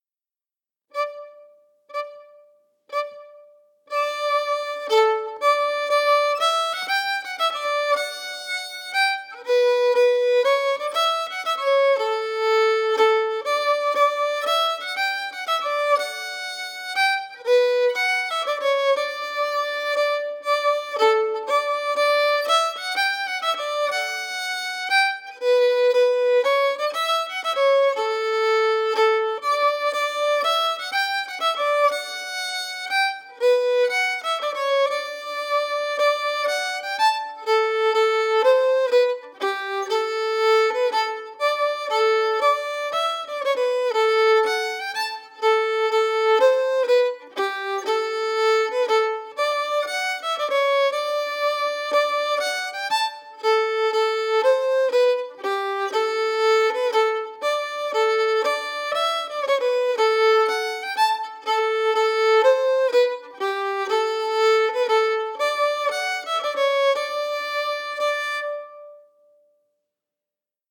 Key: D
Form:March
Boys-Lament-For-His-Dragon-slow-audio.mp3